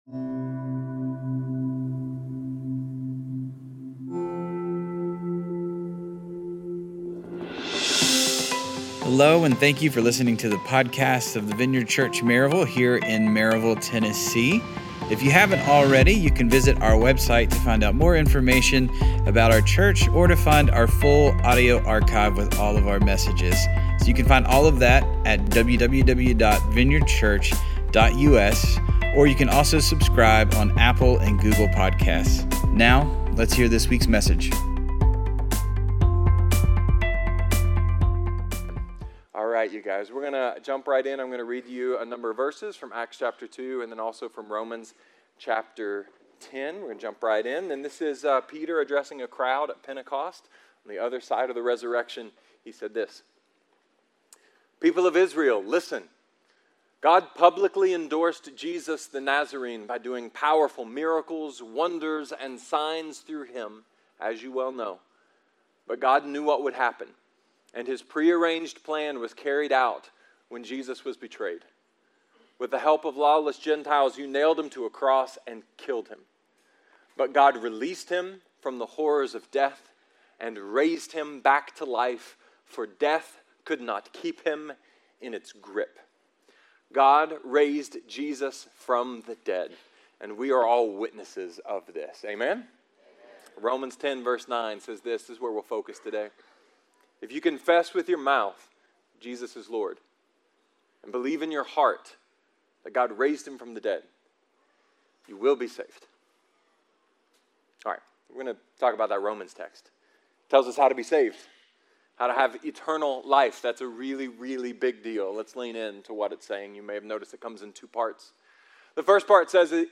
A sermon about sacrifice, death, and RESURRECTION!